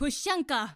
hornet poshanka Meme Sound Effect
Category: Games Soundboard